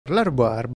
prononciation ↘ explication La rhubarbe officinale est originaire de Chine, du Tibet.